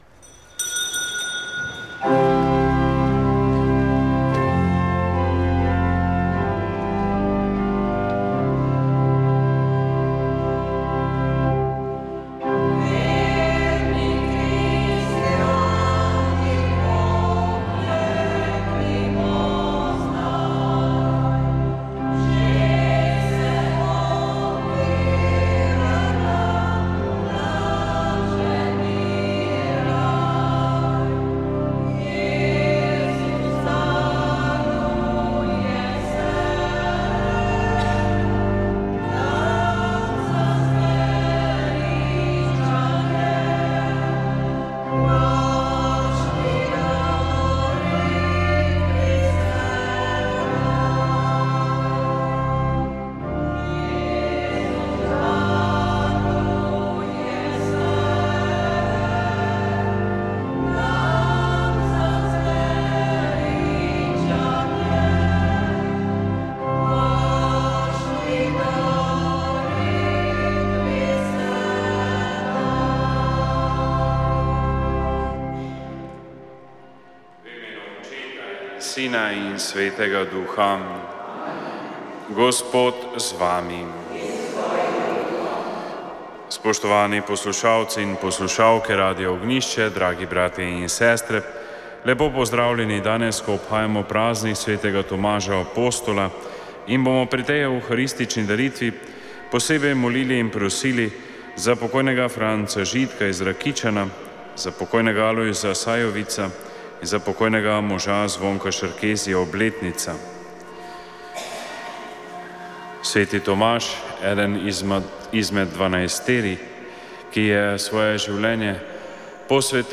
Sveta maša
Sv. maša iz cerkve Marijinega oznanjenja na Tromostovju v Ljubljani 21. 12.